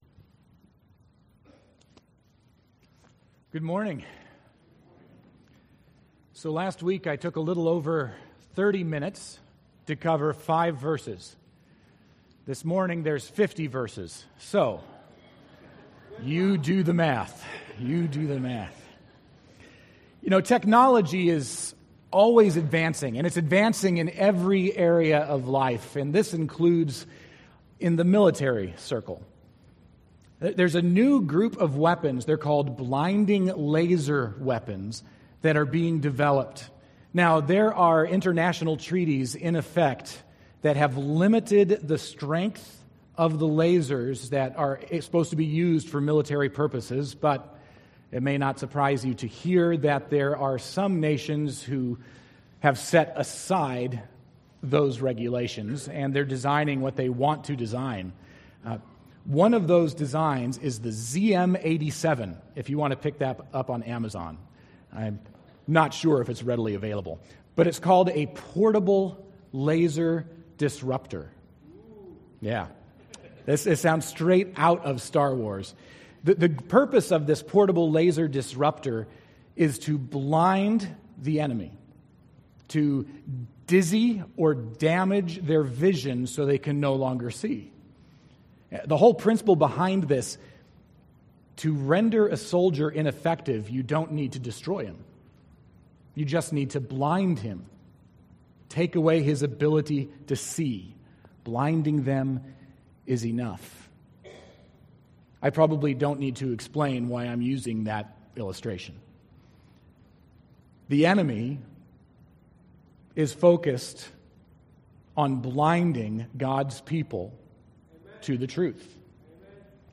Sermon9.2.18.mp3